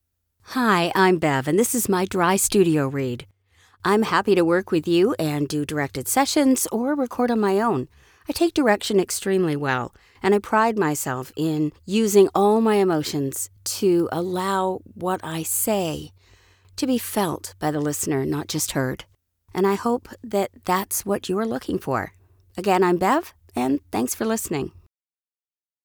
Female
Bright, Confident, Corporate, Friendly, Natural, Posh, Reassuring, Smooth, Soft, Warm, Versatile, Young, Approachable, Authoritative, Conversational, Energetic, Engaging, Upbeat
Canadian (native) neutral North American British RP
commercial.mp3
Audio equipment: professionally built booth / UR22mkII interface